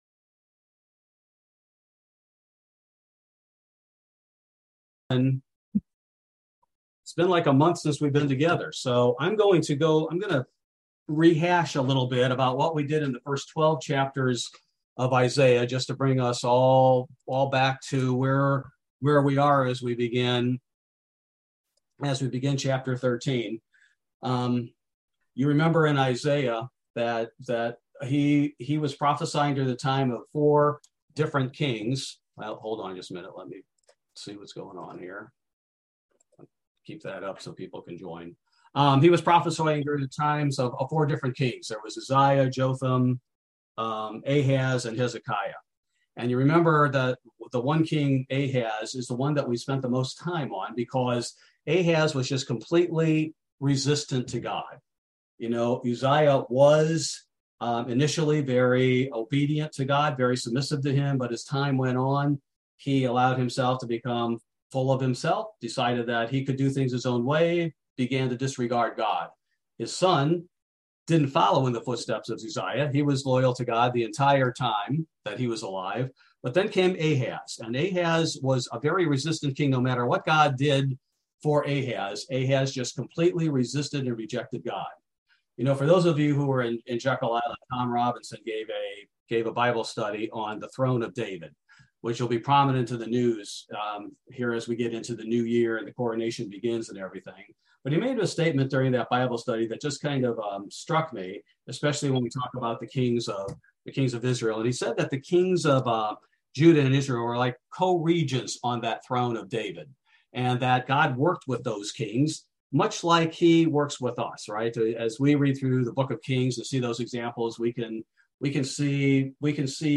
Bible Study: October 26, 2022